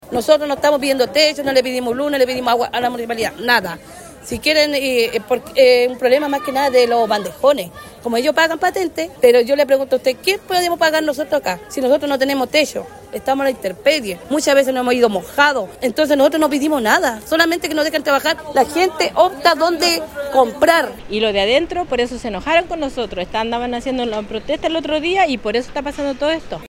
En conversación con La Radio, comentaron que solo piden que los dejen vender sus productos